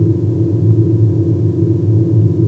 starship.wav